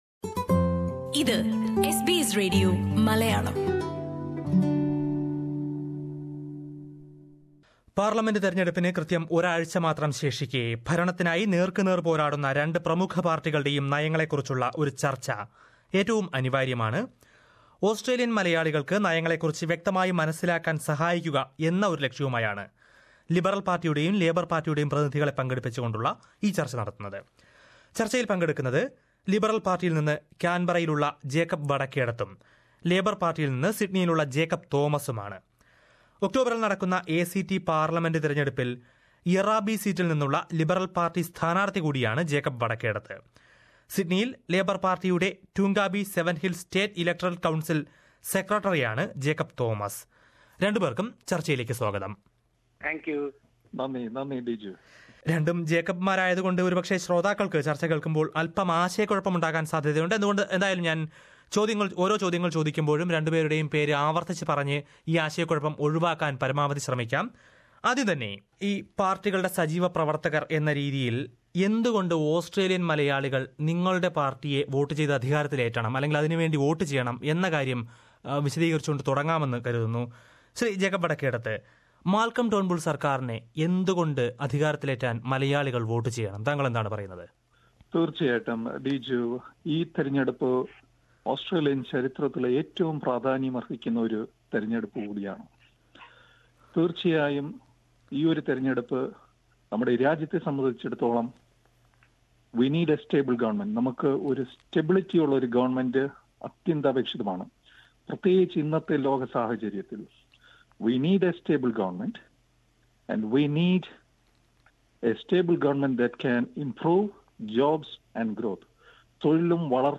തെരഞ്ഞെടുപ്പിന് ഒരാഴ്ച മാത്രം ശേഷിക്കേ രണ്ടു പ്രമുഖ പാര്‍ട്ടികളുടെയും നയങ്ങളെയും പ്രതീക്ഷകളെയും കുറിച്ച് ഒരു രാഷ്ട്രീയ സംവാദം